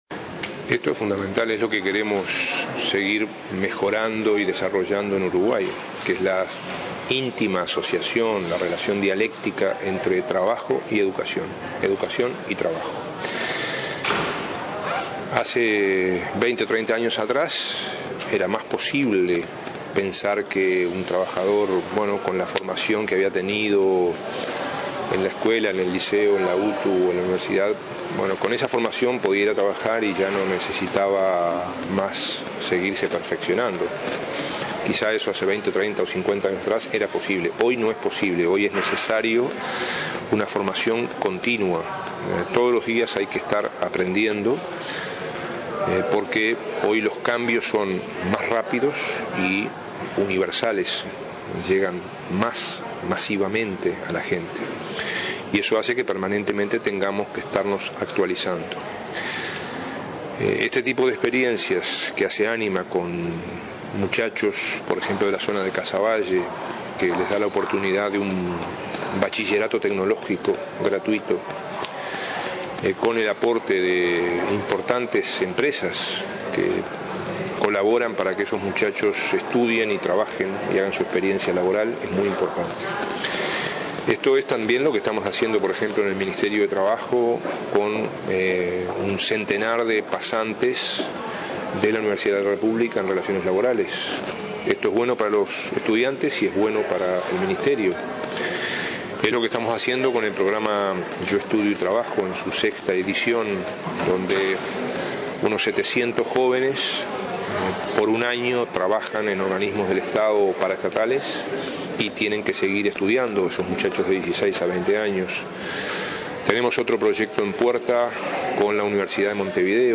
El Secretario de Estado participó este viernes del evento “Empresas formadoras: trabajamos aprendiendo” organizado por Ánima.